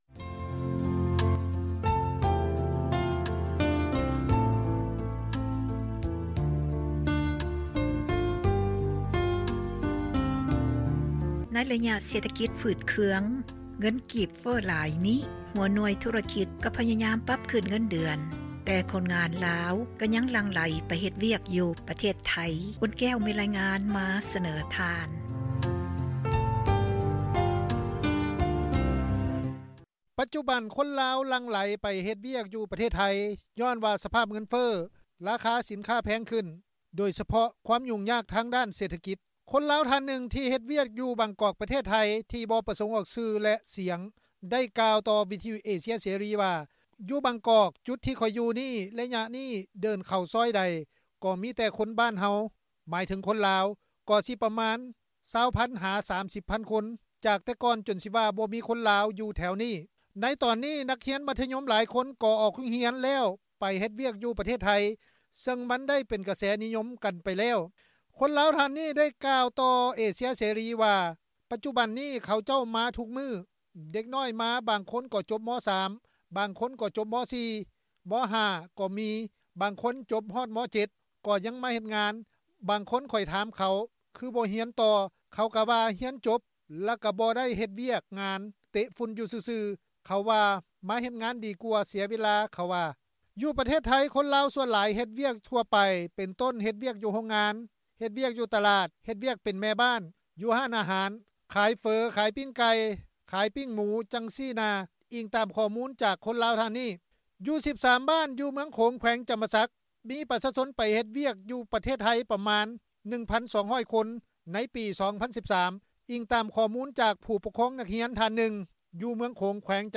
ປະຊາຊົນ ຢູ່ເມືຶອງໂຂງ ທ່ານນຶ່ງ ໄດ້ກ່າວຕໍ່ເອເຊັຽເສຣີ ວ່າ:
ເຈົ້າໜ້າທີ່ກົມຈັດຫາງານ ກະຊວງແຮງງານ ແລະ ສະຫວັດດີການສັງຄົມ ໄດ້ກ່າວຕໍ່ເອເຊັຽເສຣີ ວ່າ: